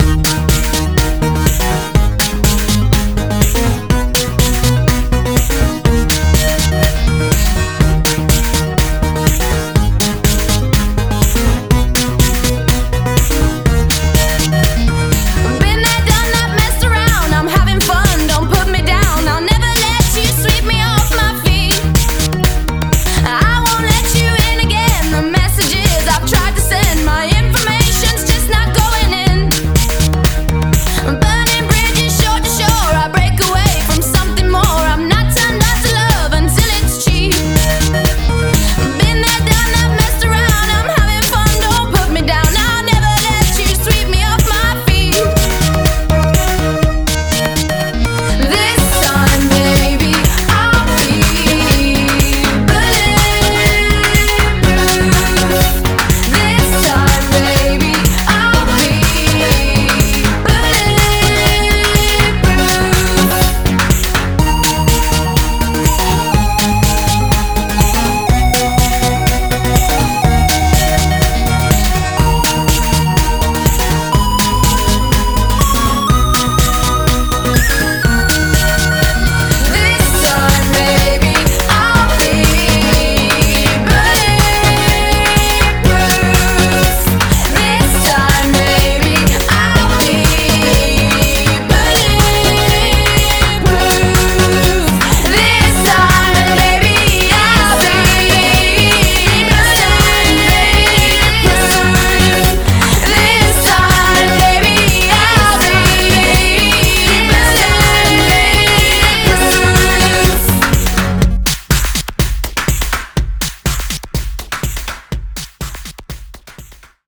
BPM123--1
Audio QualityMusic Cut